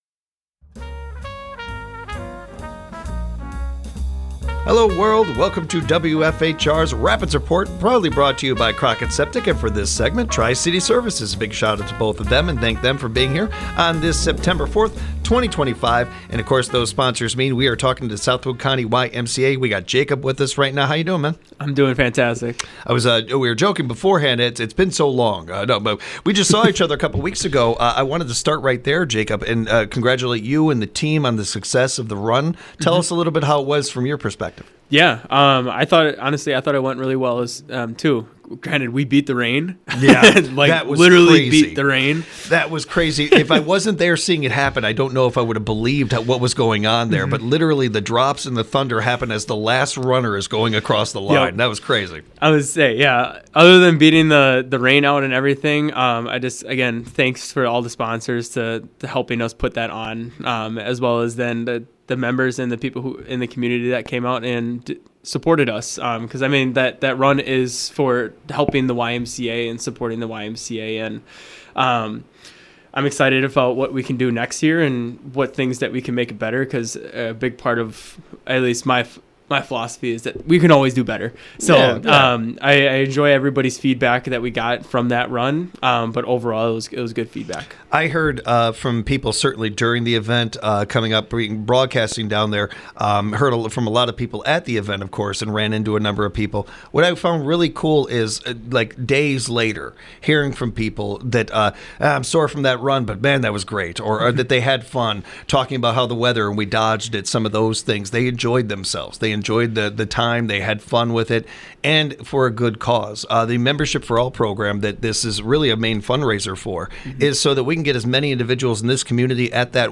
A Special thank you to Tri City Services for sponsoring this interview.